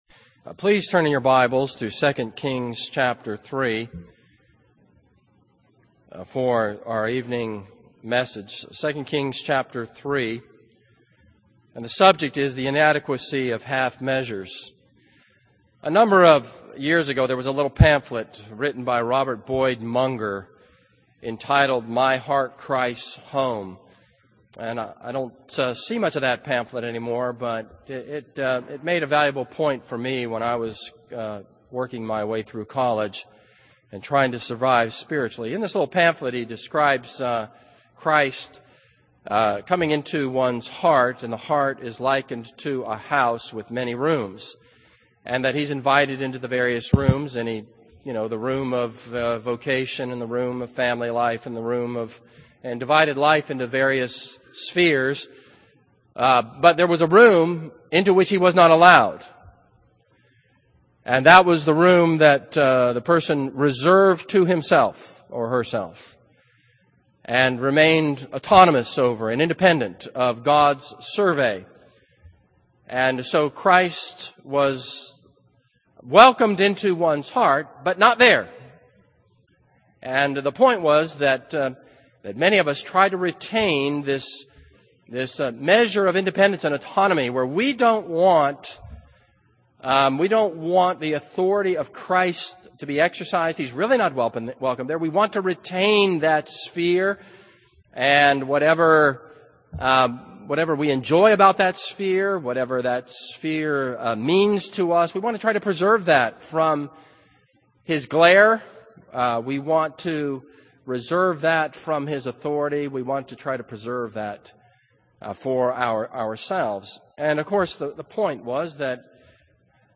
This is a sermon on 2 Kings 3.